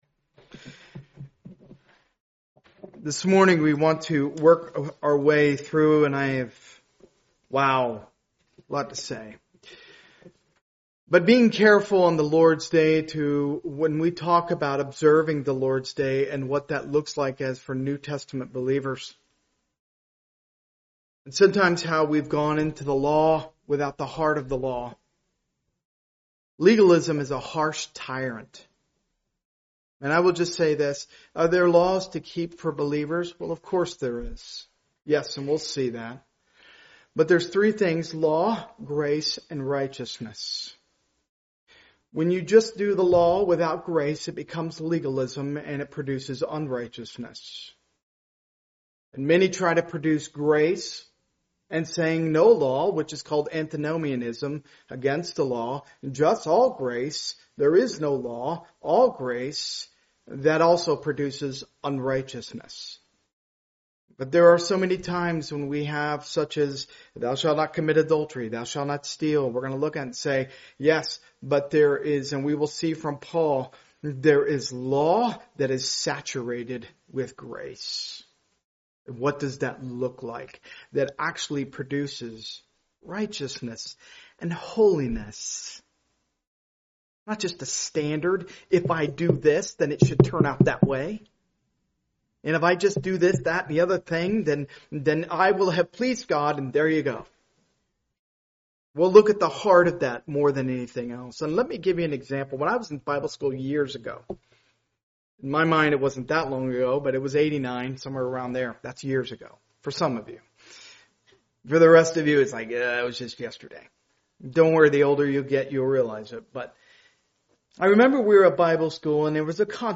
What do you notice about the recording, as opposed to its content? Part of the Topical series, preached at a Morning Service service.